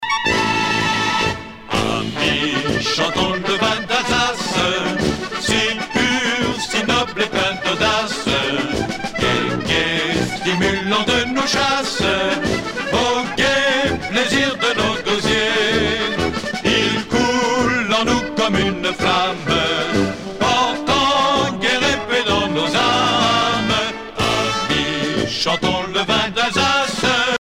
danse : marche ;
Pièce musicale éditée